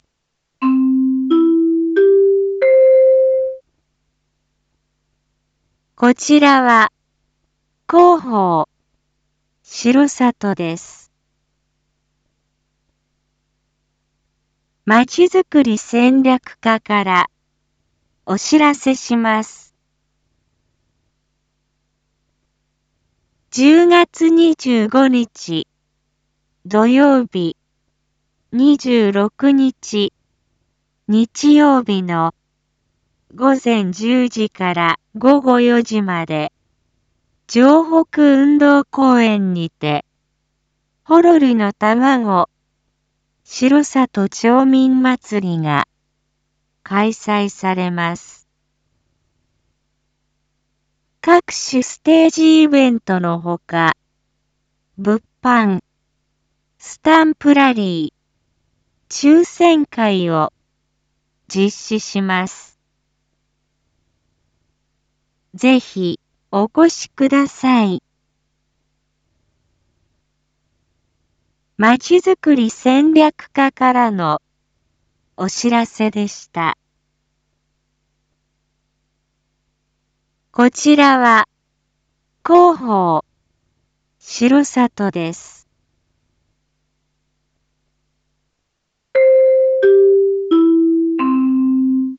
Back Home 一般放送情報 音声放送 再生 一般放送情報 登録日時：2025-10-22 19:01:34 タイトル：R7しろさと町民まつり インフォメーション：こちらは、広報しろさとです。